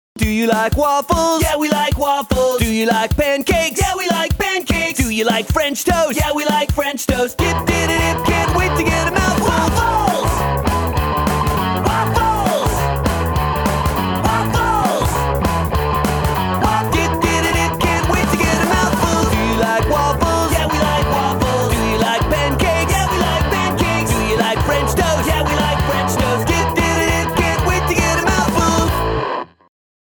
BPM: 100.7